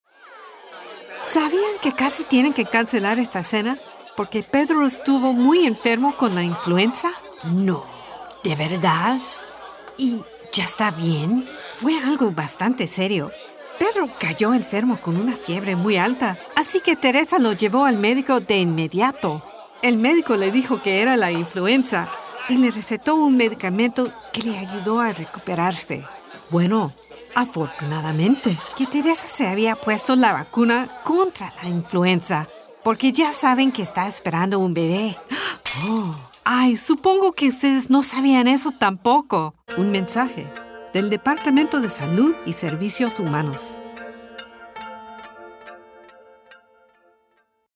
a smooth and resonant voice
Spanish / Mexican accent: The Dinner Party (gossipy)